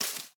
Minecraft Version Minecraft Version 1.21.5 Latest Release | Latest Snapshot 1.21.5 / assets / minecraft / sounds / block / azalea_leaves / break1.ogg Compare With Compare With Latest Release | Latest Snapshot
break1.ogg